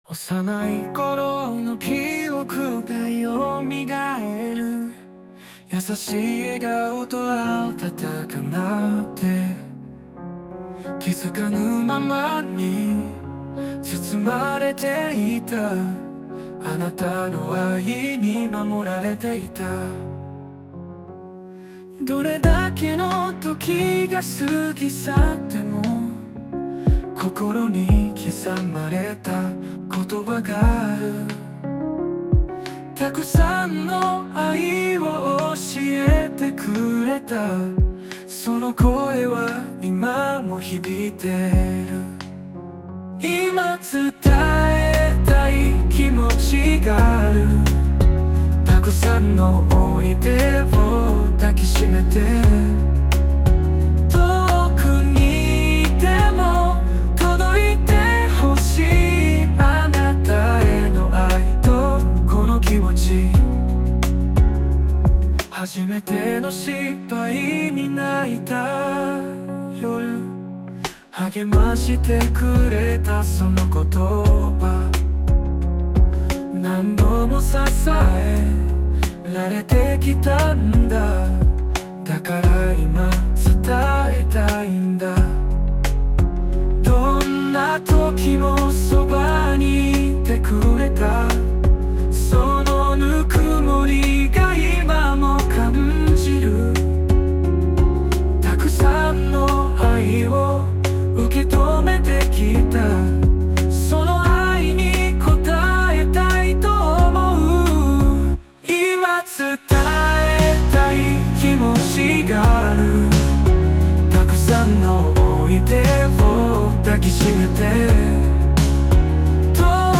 男性ボーカル邦楽 男性ボーカルプロフィールムービー
著作権フリーBGMです。
男性ボーカル（邦楽）曲です。